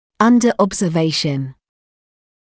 [‘ʌndə ˌɔbzə’veɪʃn][‘андэ ˌобзэ’вэйшн]под наблюдением